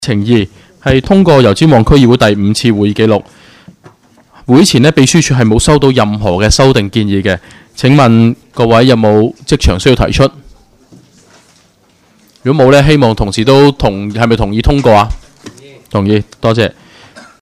区议会大会的录音记录
油尖旺区议会第六次会议